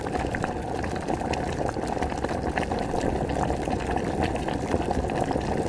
沸腾的水 2
描述：短暂的沸水时刻，麦克风有点太远......但声音很好